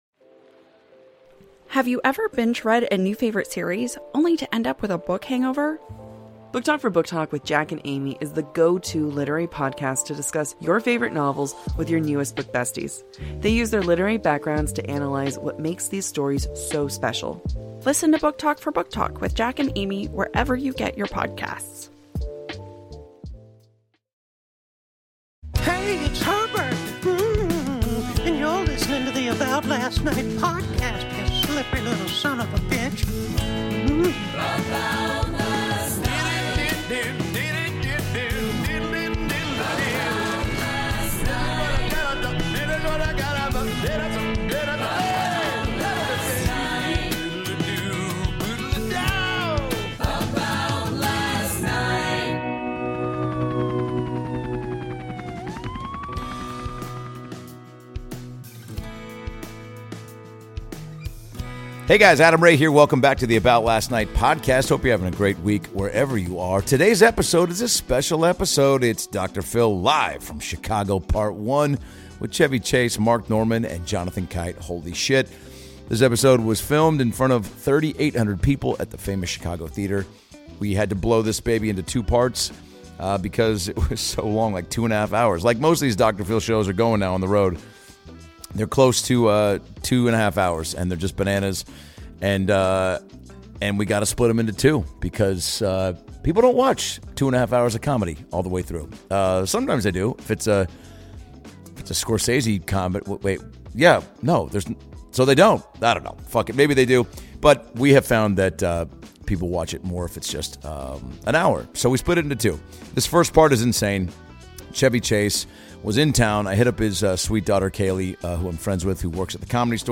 Dr. Phil LIVE! is coming to you from Chicago with a HUGE WILD show featuring the infamous Chevy Chase, the brilliant Mark Normand and the unpredictable Jonathan Kite!